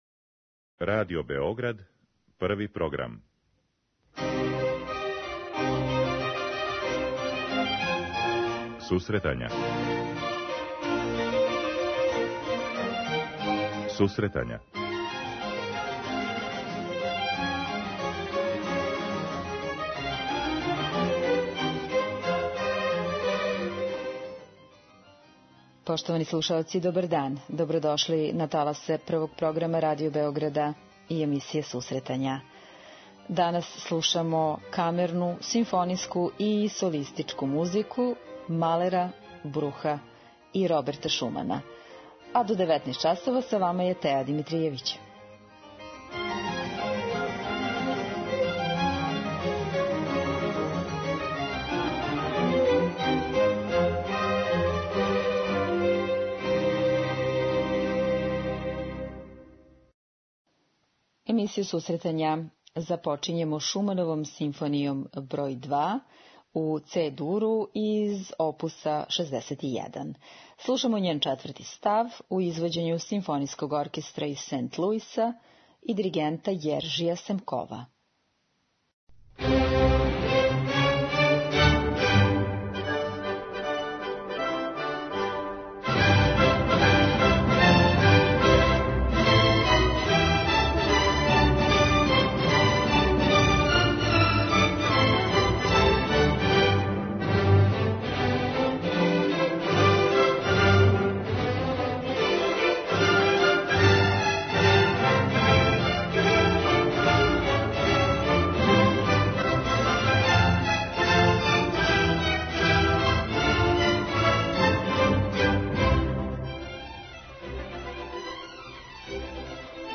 Данашњу емисију посветили смо камерној, солистичкој и симфонијској музици Густава Малера и Макса Бруха као и одабраним делима Роберта Шумана.